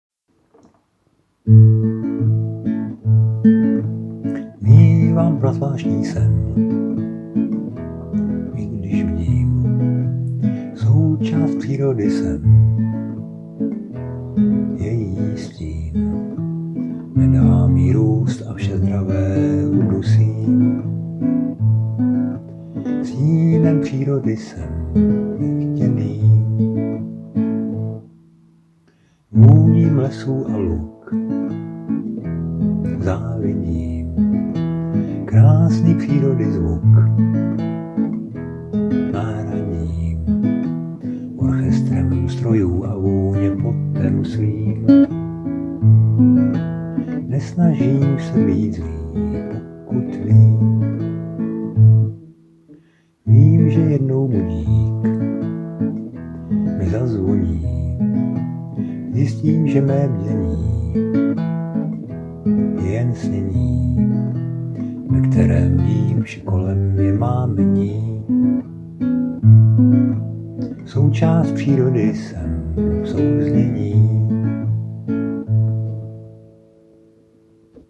Anotace: Písničkobásničkokravinka:-)
Obdivuhodný text, moc se mi líbí, brnkání je skvělé, jen ten zpěv - nezvyknu si, promiň - úsměv.